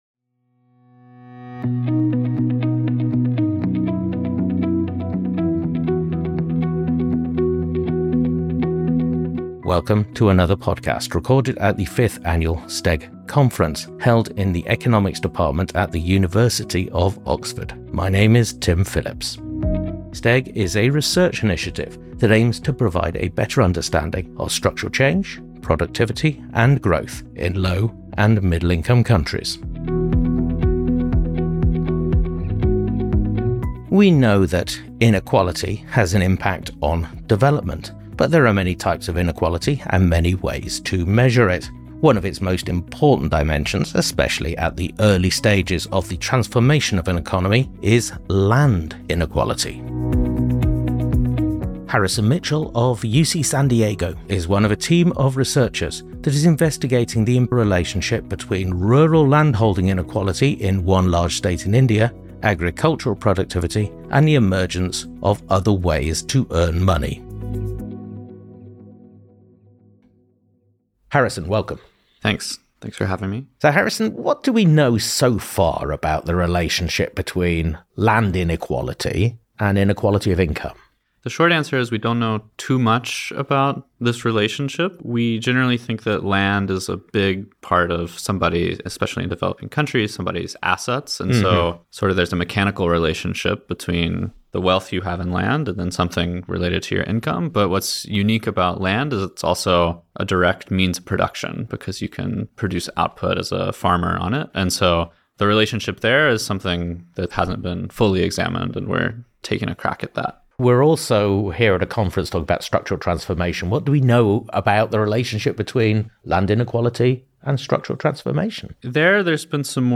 Recorded at the 2025 annual conference of the Structural Transformation and Economic Growth Programme, held at the University of Oxford.